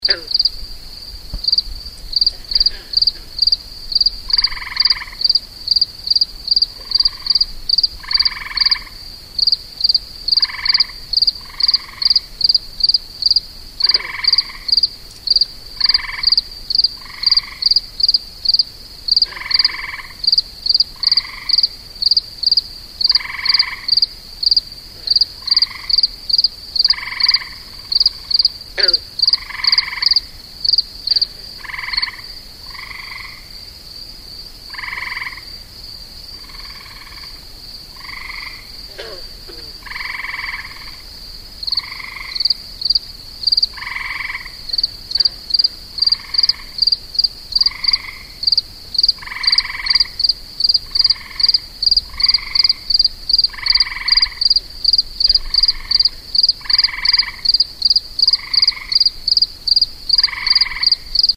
I love to walk down to the shelter around midnight and listen to them singing, jumping after bugs and minnows and issuing forth with an occasional burp.
Audio Player: Hills Pond at Midnight in Early June
hills-pond-at-midnight-gray-tree-frogs-green-frogs-6-07-09.mp3